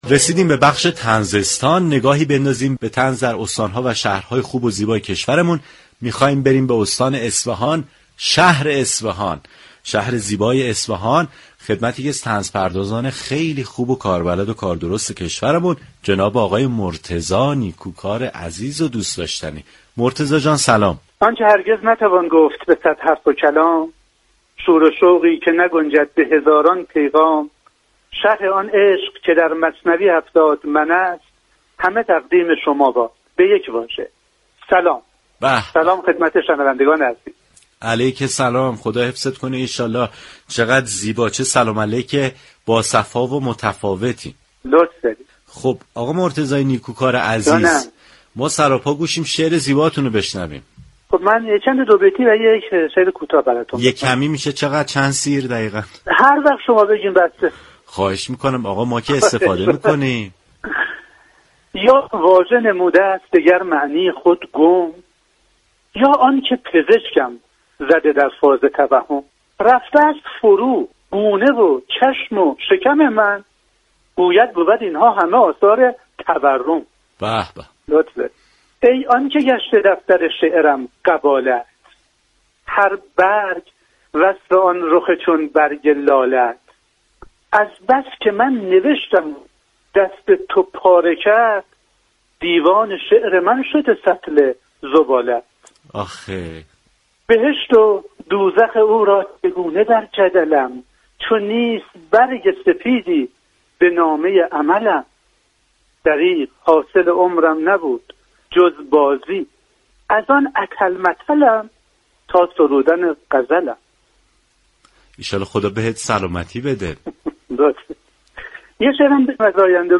گفتگوی تلفنی